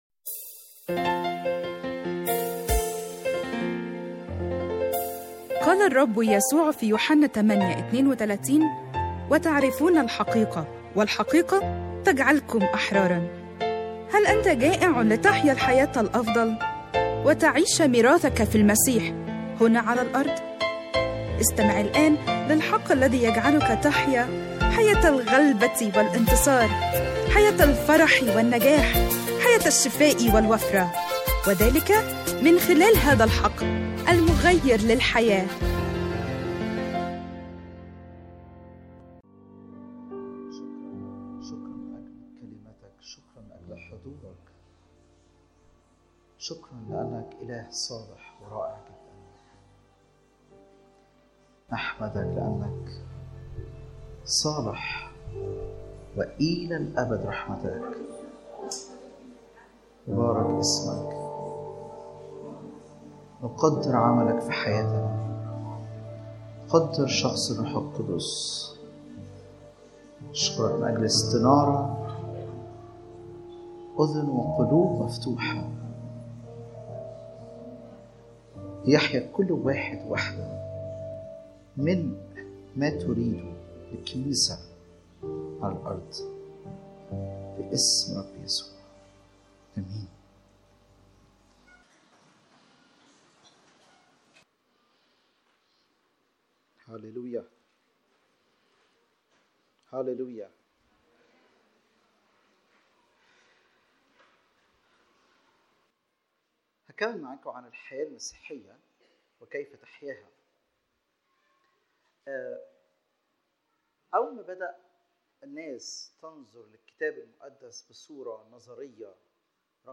العظة مكتوبة
اجتماع الثلاثاء 30/3/2021 السلسلة مكونه من 8 أجزاء